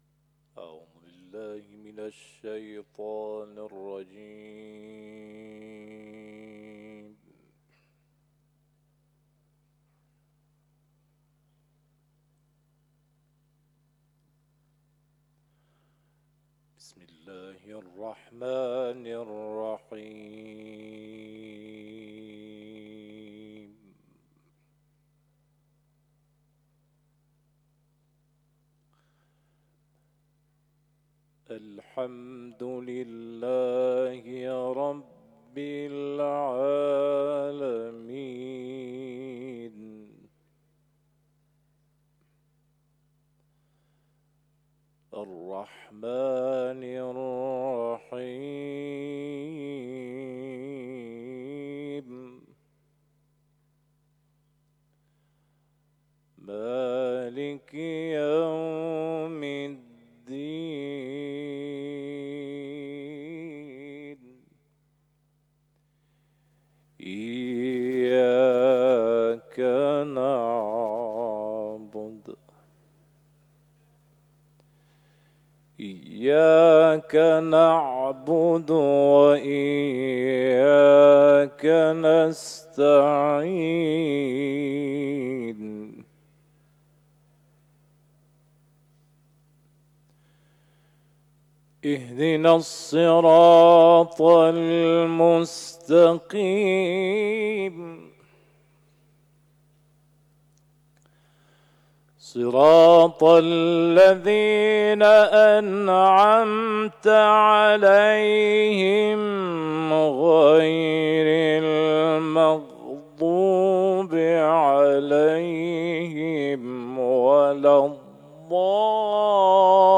تلاوت
قاری